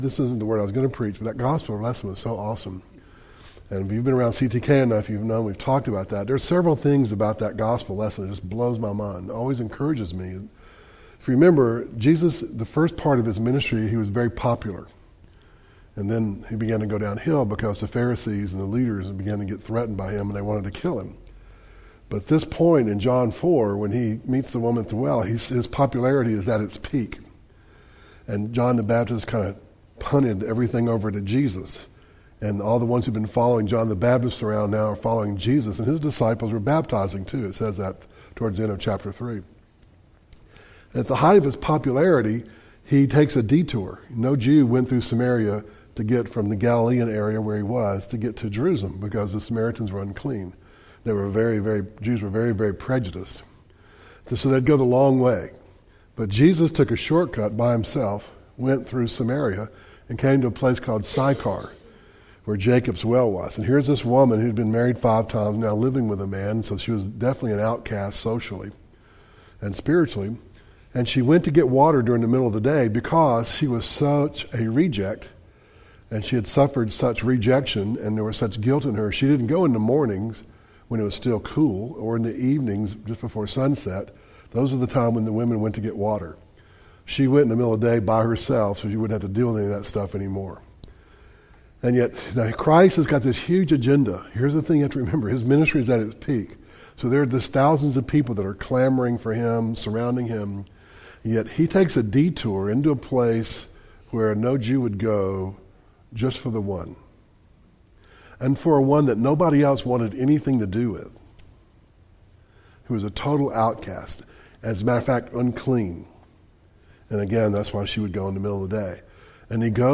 Audio Devotionals Woman at the Well